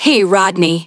synthetic-wakewords
ovos-tts-plugin-deepponies_Naoto Shirogane_en.wav